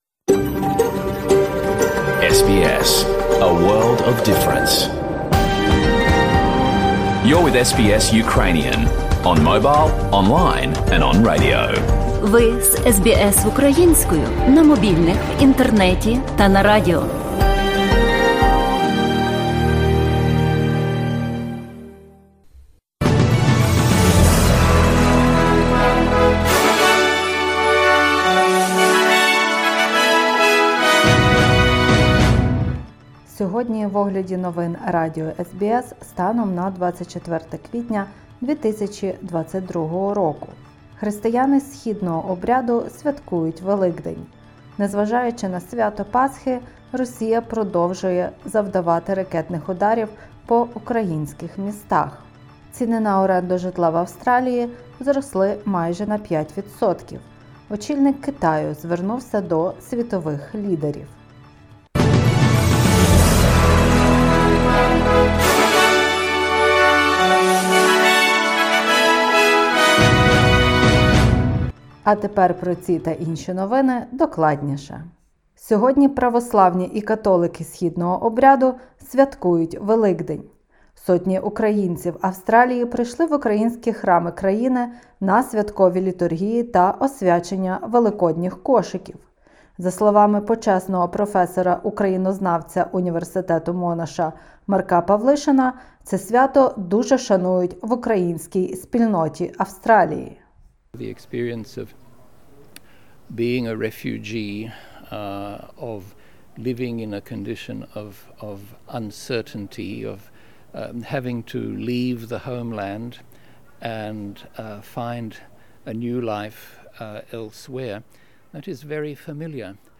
Бюлетень SBS новин українською мовою.